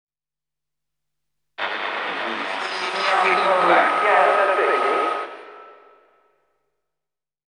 Ambient
1 channel